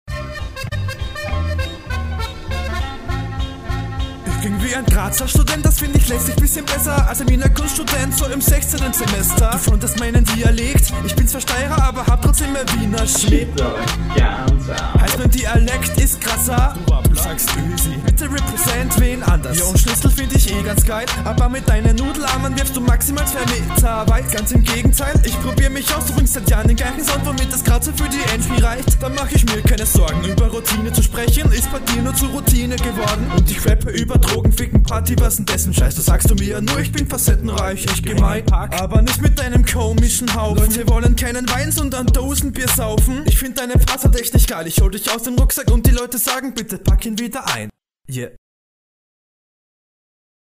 Soundmische war leider schlecht aber gut gekontert war die Grazerstudent line gut ausgenommen und punchend.
Hängst hier wieso auch immer dem Beat bissel hinterher.